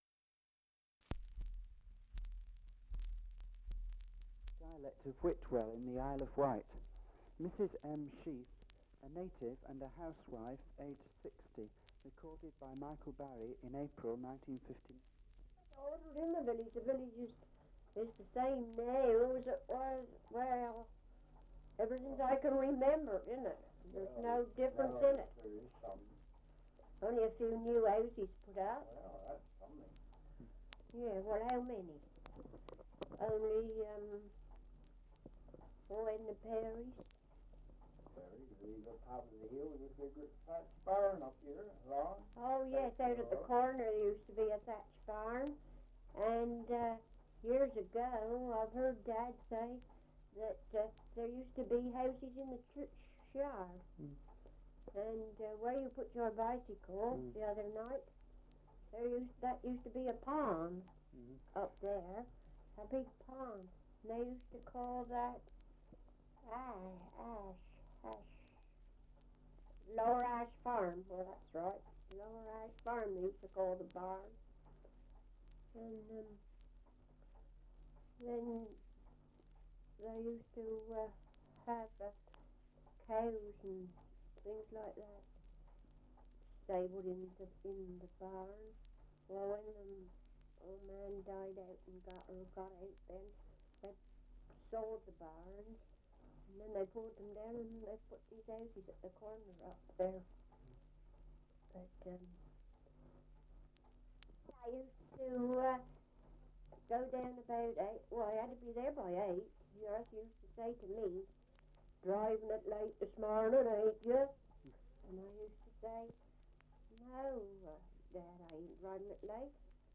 Survey of English Dialects recording in Whitwell, Isle of Wight
78 r.p.m., cellulose nitrate on aluminium